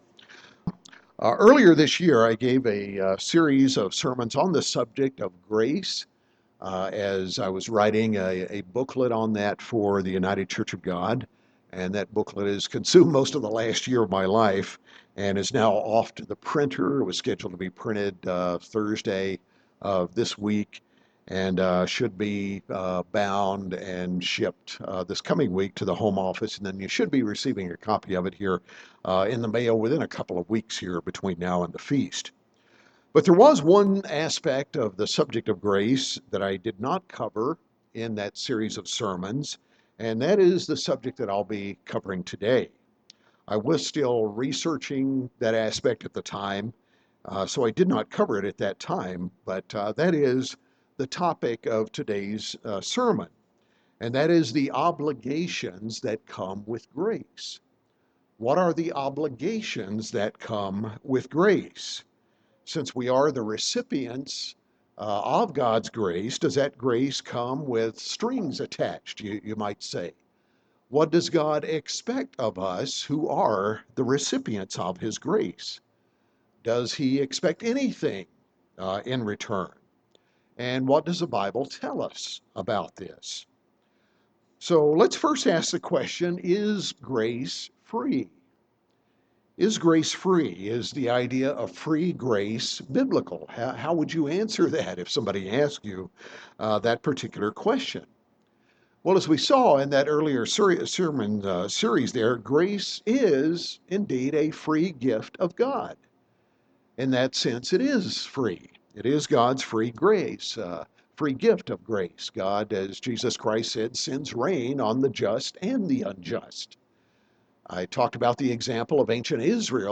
In this conclusion to a series of sermons on grace, we’ll answer the question what are the obligations that come with grace?